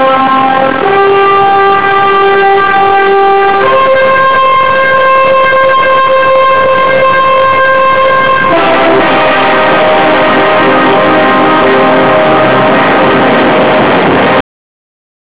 Mars Microphone: Test Sound Data from Mars Wind Tunnel